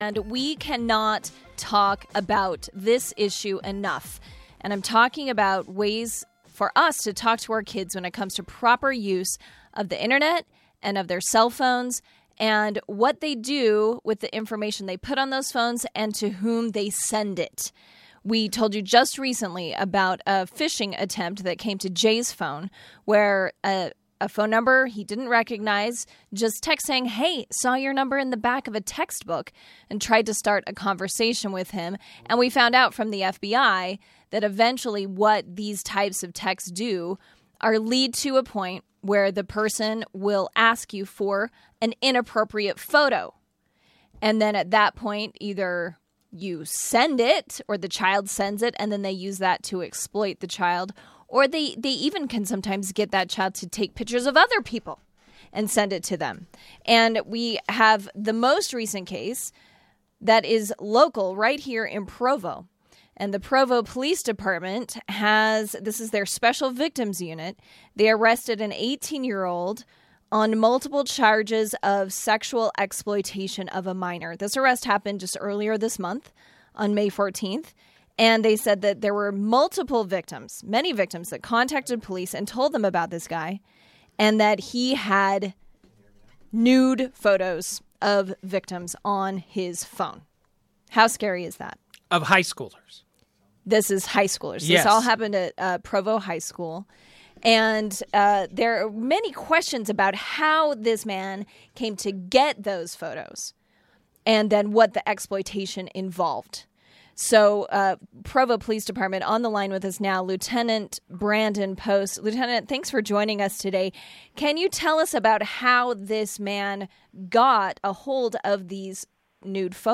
Interview: How to help your teen 'get' the danger related to sexting